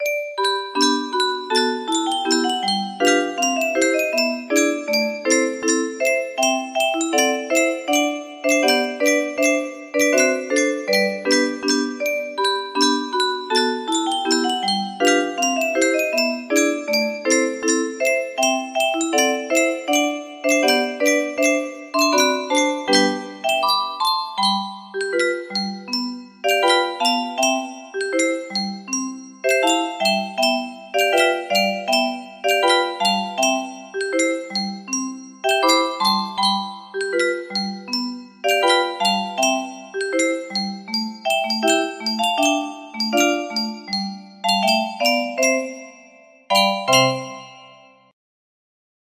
Andrés Díaz de la Vega - Mi concha music box melody